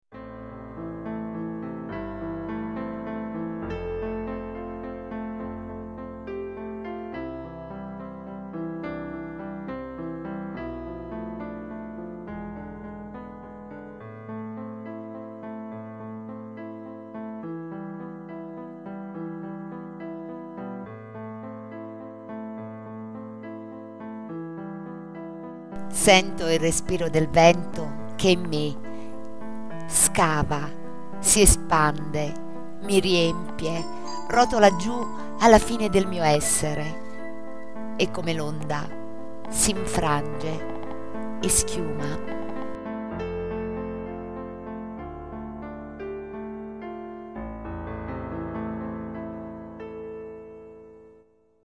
ps. ps. L’ho letta io :)